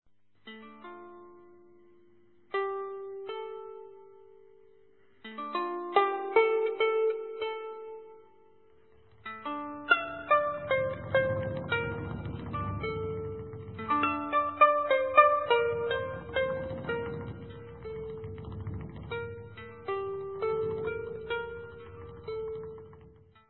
harpe du manuscrit des Cantigas de Santa-Marie, curbel